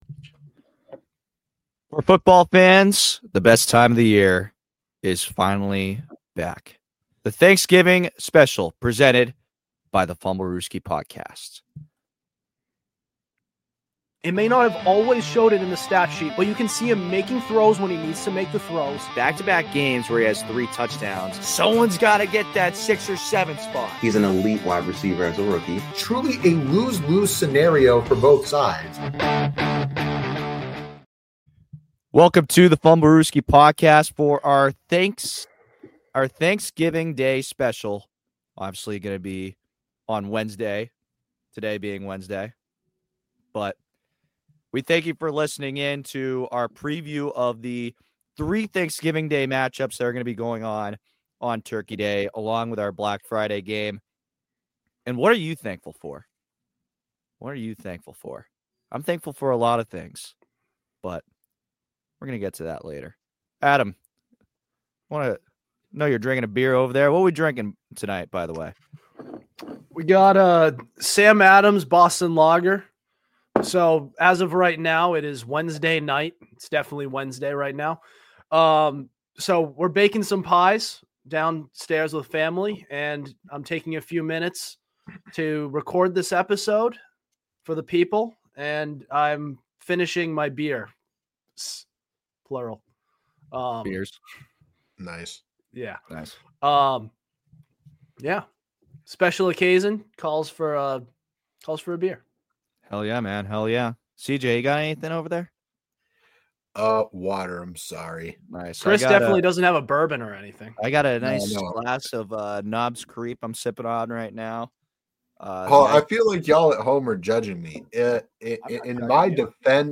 An NFL podcast where we discuss all things pro-football-related, including recent news and hot takes. Hosted by four college guys